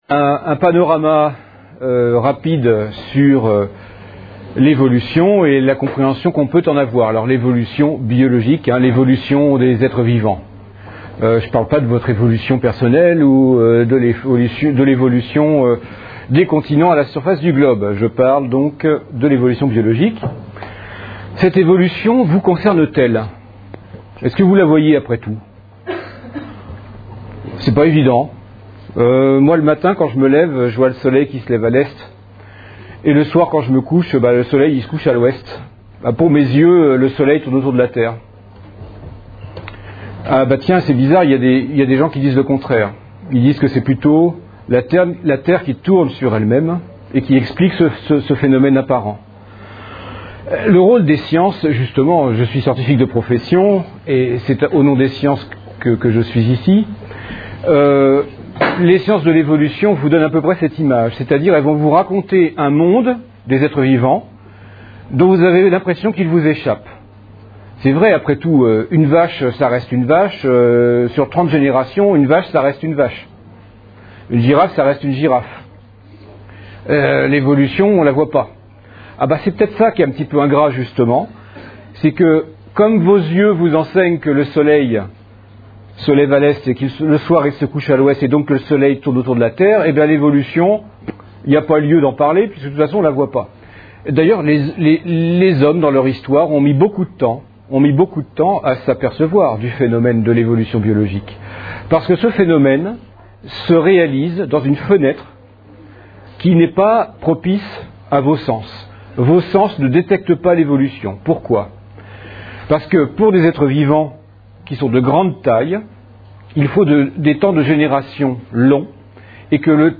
Une conférence de l'UTLS au Lycée La théorie de l'évolution par Guillaume Lecointre Lycée des Flandres (59 Hazebrouck)